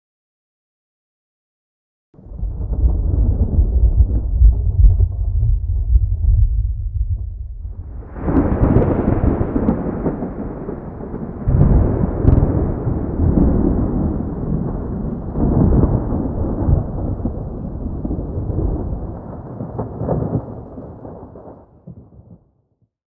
thunder-3-hec.ogg